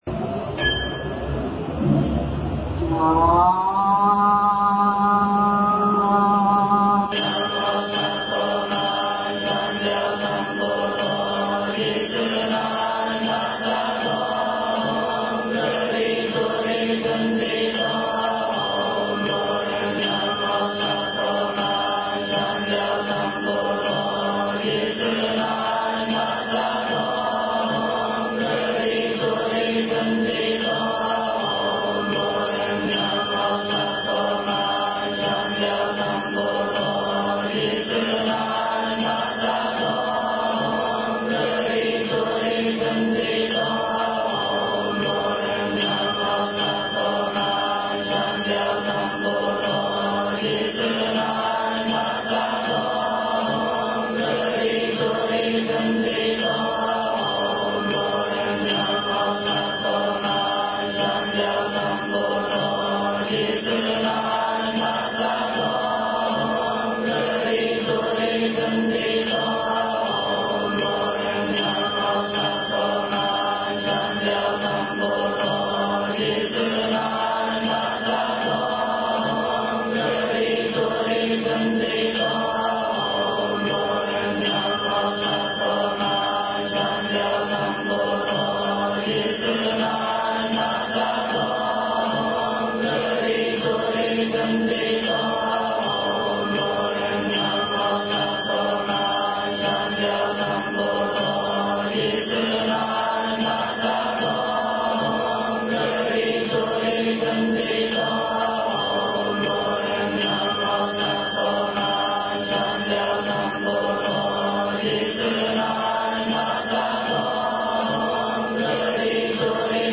准提咒（念诵）